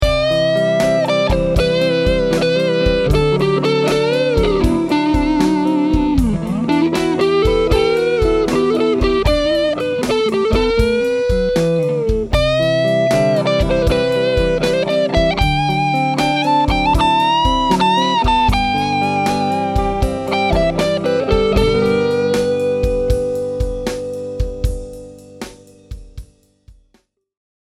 I was expecting it to be bright, but it’s thick and ballsy, with a real emphasis on the lower mids.
Neck Pickup
Dirty Lead
I used a Sennheiser e609 instrument mic, and recorded directly into GarageBand with no volume leveling.